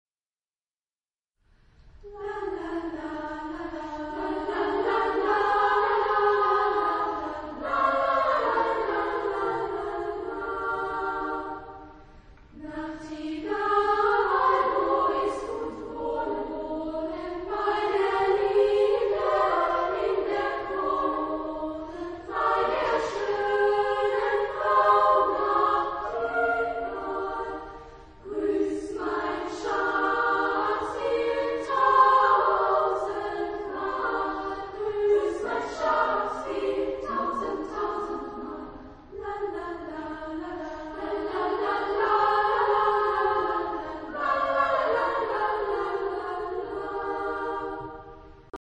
Genre-Stil-Form: Volkslied
Chorgattung: SSA  (3 Frauenchor Stimmen )